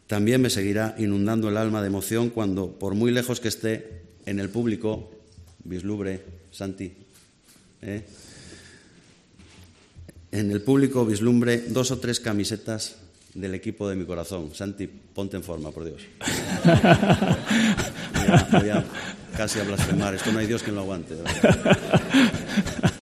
Este lunes ha recibido la distinción de Hijo Predilecto de la ciudad y Santi Cazorla ha querido acompañarle en el Auditorio Príncipe Felipe. Un cóctel que se ha completado, a petición del alcade Alfredo Canteli, con la interpretación a capela del 'Volveremos' que el propio Melendi compuso hace años.
Esto no hay quien lo aguante" comentaba el artista entre las risas del público asistente".